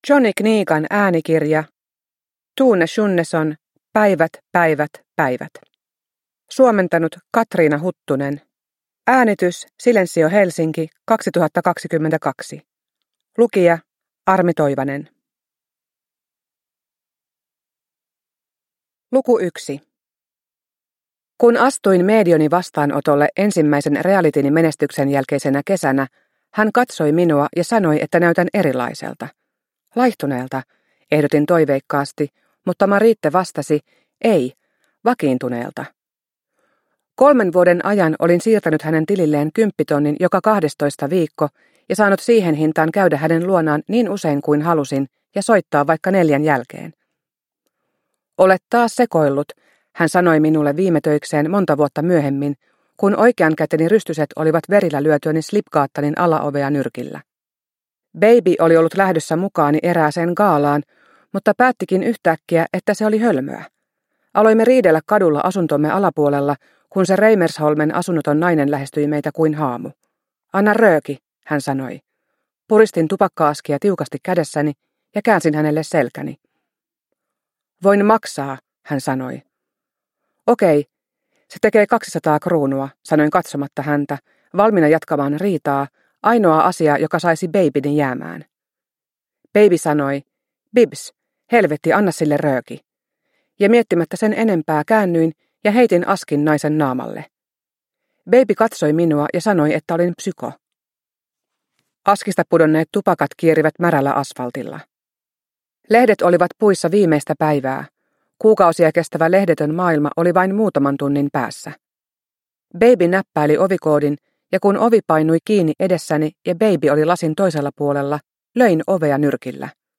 Päivät, päivät, päivät – Ljudbok – Laddas ner